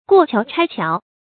過橋拆橋 注音： ㄍㄨㄛˋ ㄑㄧㄠˊ ㄔㄞ ㄑㄧㄠˊ 讀音讀法： 意思解釋： 見「過河拆橋」。